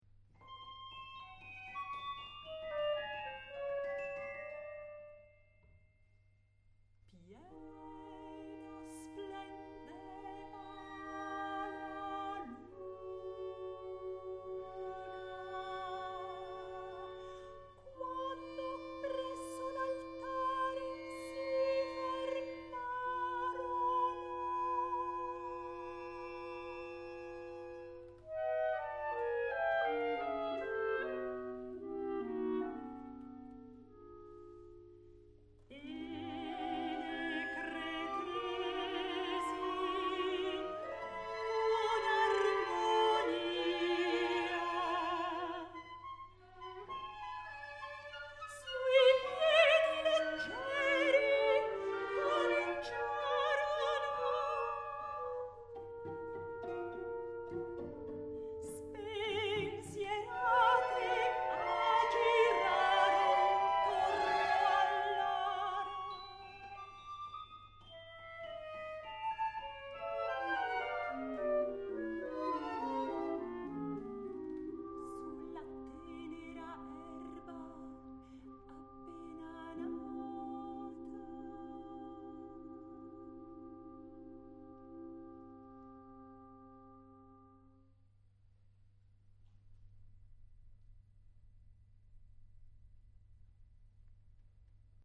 SERIALISM: